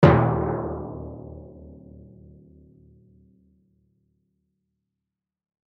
Звуки литавры
Одинокий звук литавры